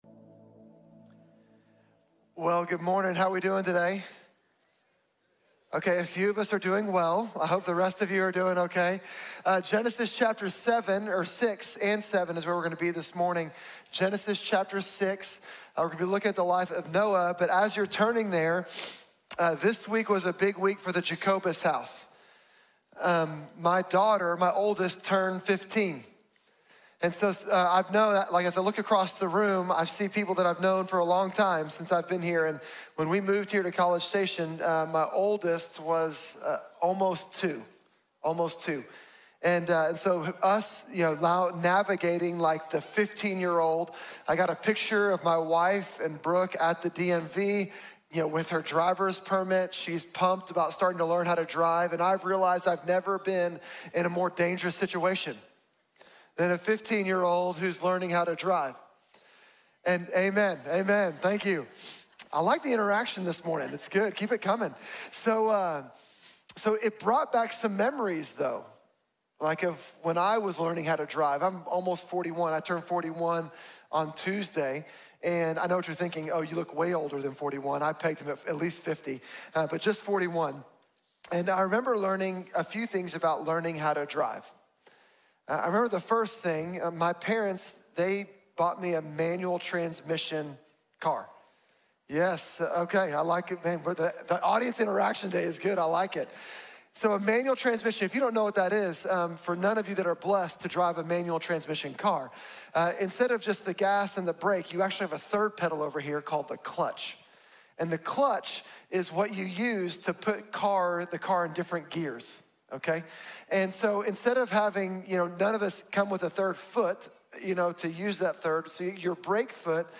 Noah: Radical Obedience | Sermon | Grace Bible Church